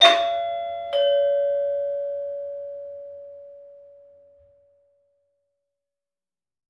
DoorBell-44k.wav